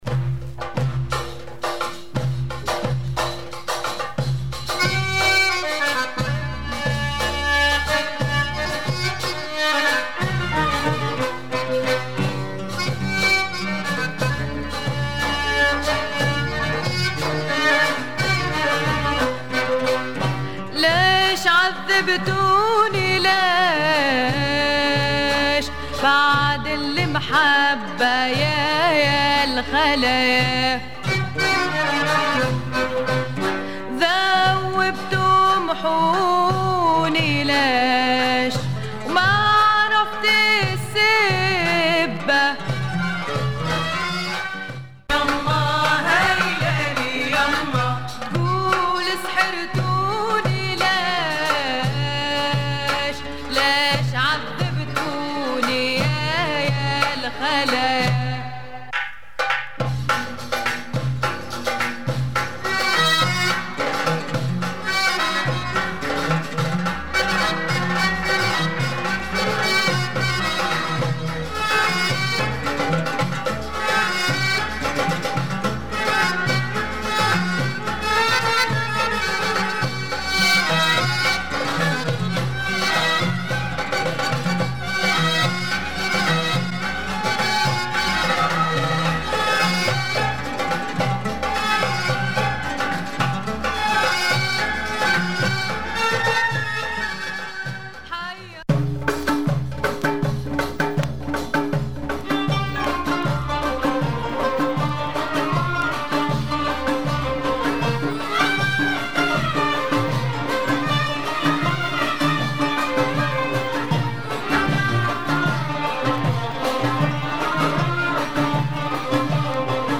Arabic & Persian